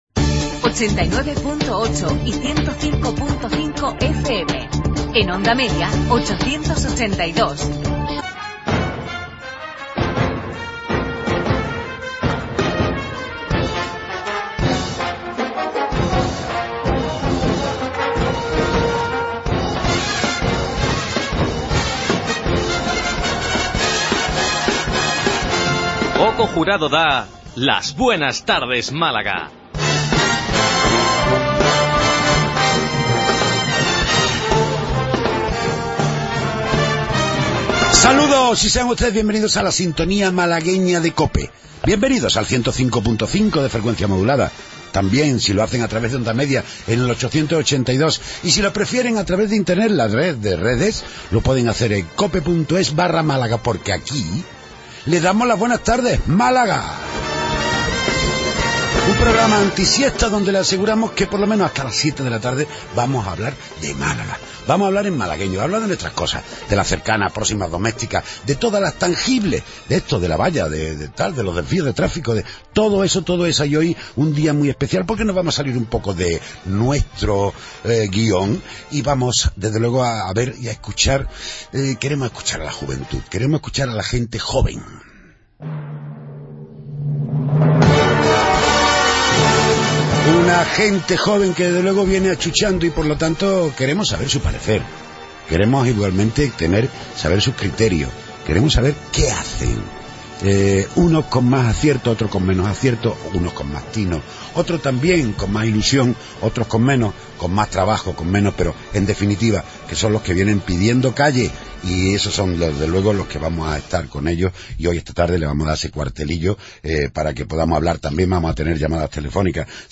Tertulia de los temas que interesan a Málaga y los Malagueños.